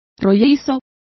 Complete with pronunciation of the translation of buxom.